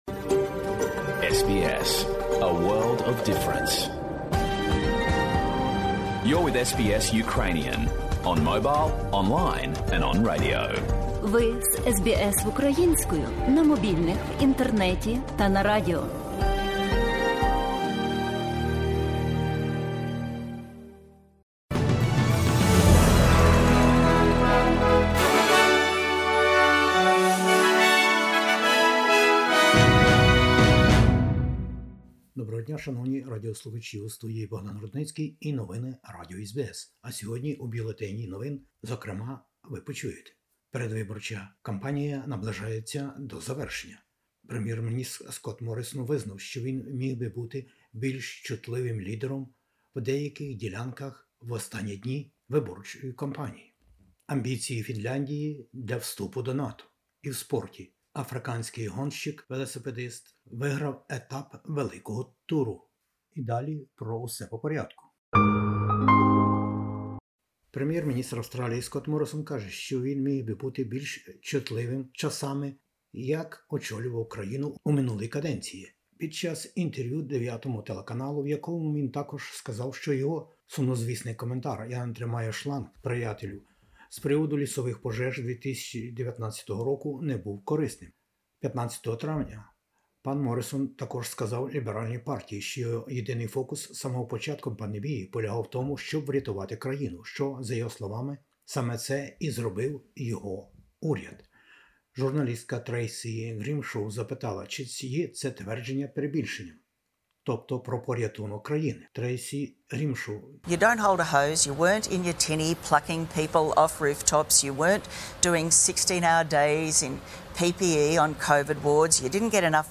Бюлетень SBS новин українською мовою. 21-го травня - федеральні вибори в Австралії, а дострокове голосування уже розпочалося на понад 500 виборчих дільницях по всій країні. Протистояння Федеральної коаліції та Австралійської Лейбористської Партії, у тому числі лідерів за прем'єрство.